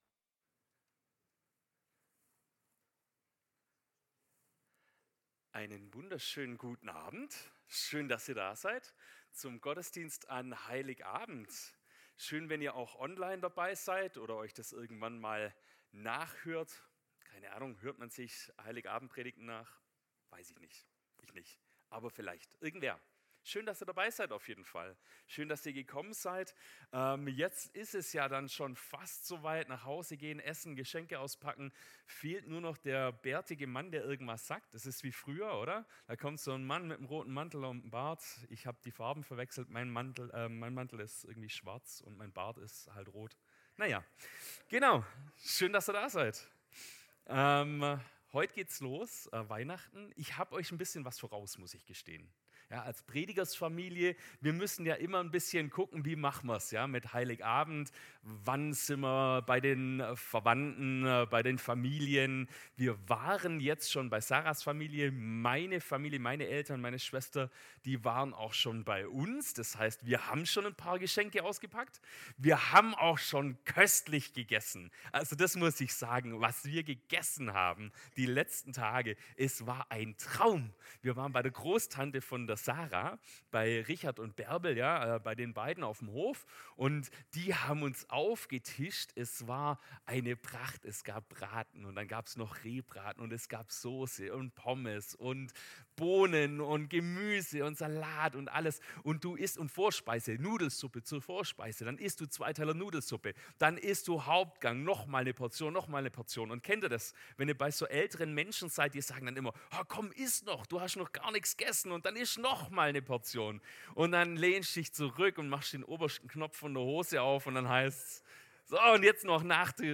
Predigt am 24.12.2025